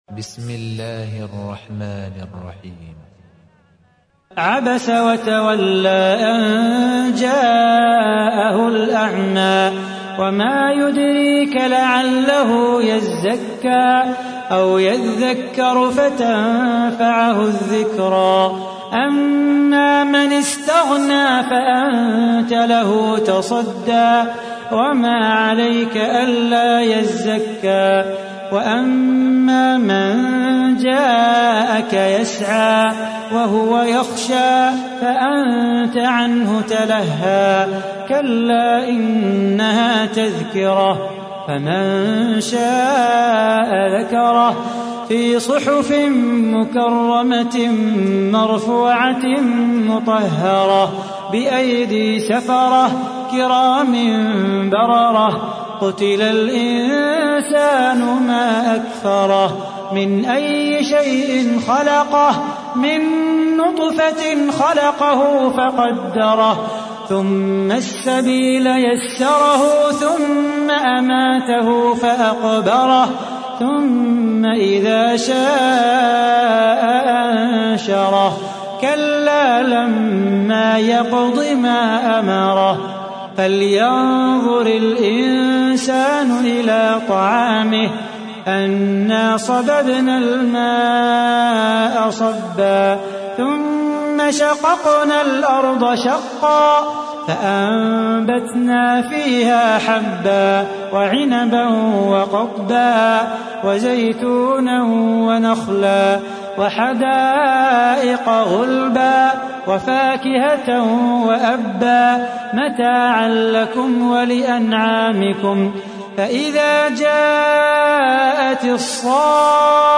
تحميل : 80. سورة عبس / القارئ صلاح بو خاطر / القرآن الكريم / موقع يا حسين